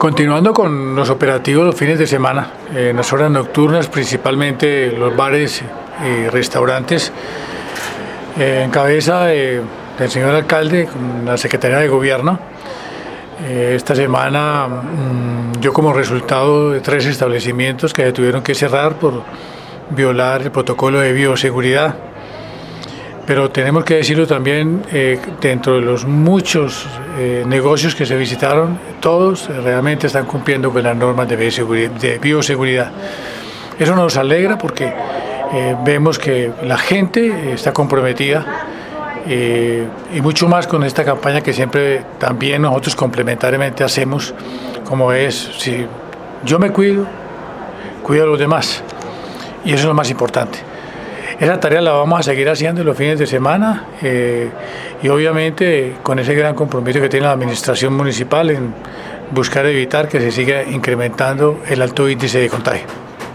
Audio: Javier Ramírez Mejía, Secretario de Gobierno